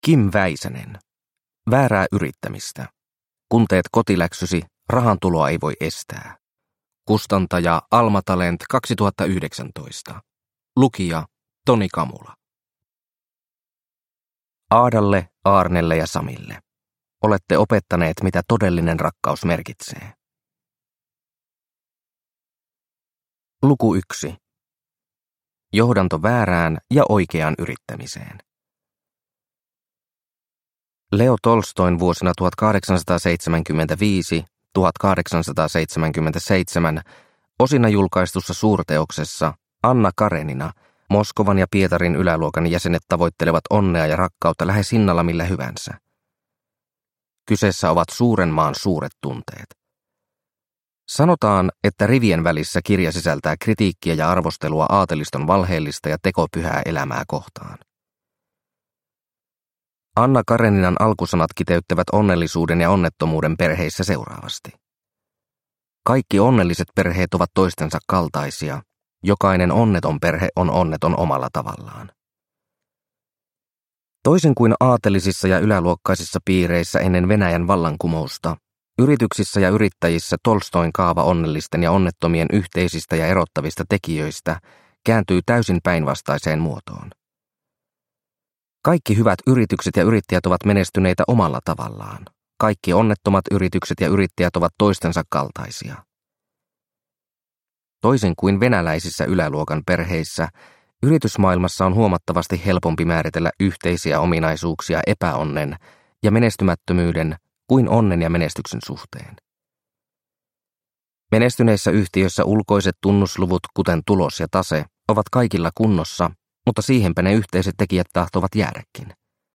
Väärää yrittämistä – Ljudbok – Laddas ner